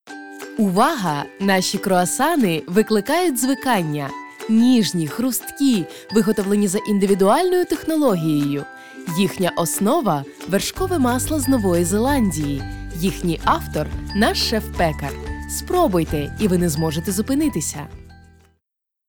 Sprecher
Tief, Natürlich, Vielseitig
Unternehmensvideo